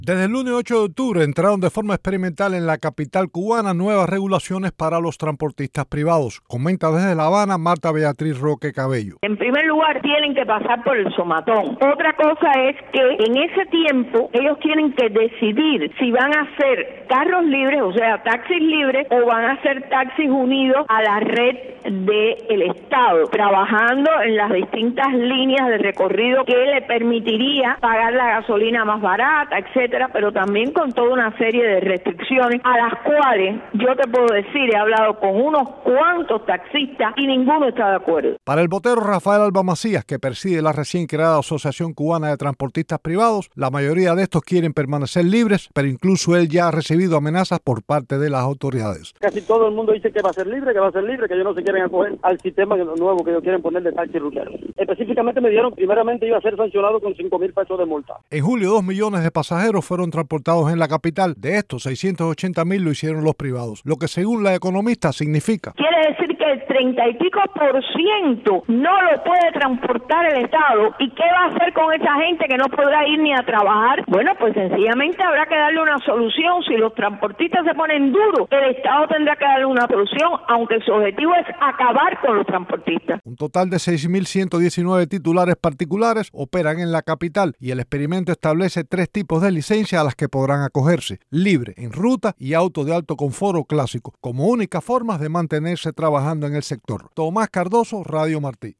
Opinan desde La Habana una economista y un transportista privado